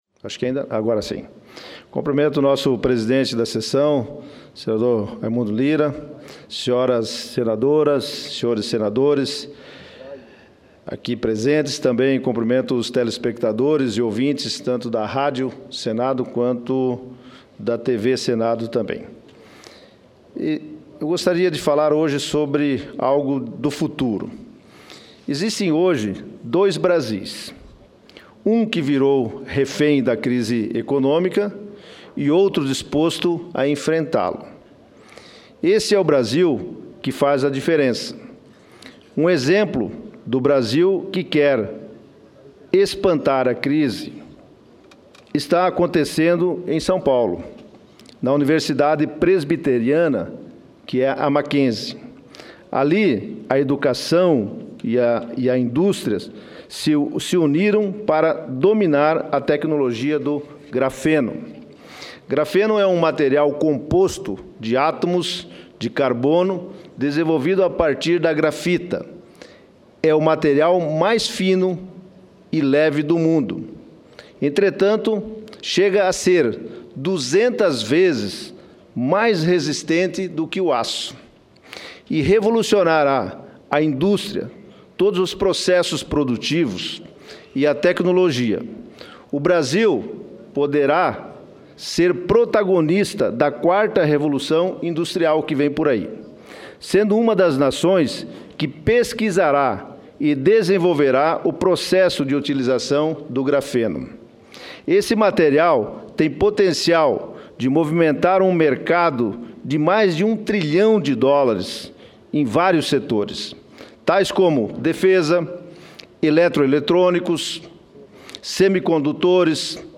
Dsicursos